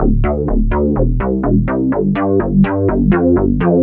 cch_bass_loop_neworder_125_Dm.wav